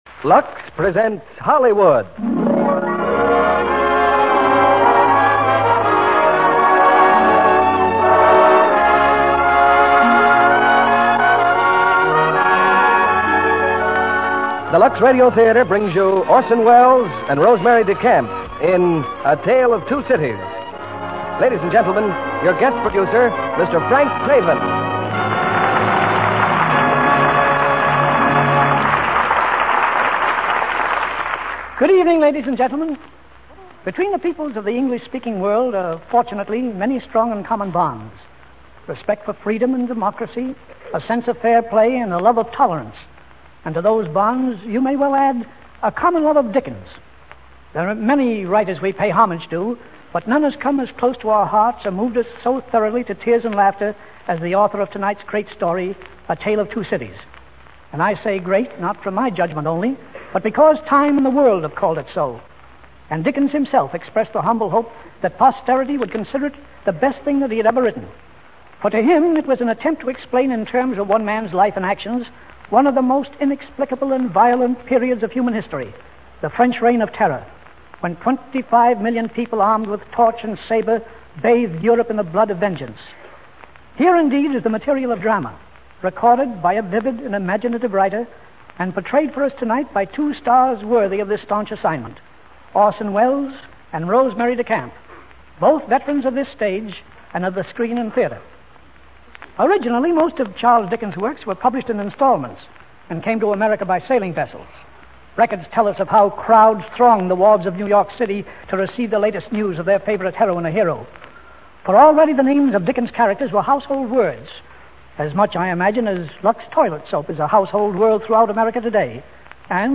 Lux Radio Theater Radio Show
A Tale of Two Cities, starring Orson Welles, Rosemary DeCamp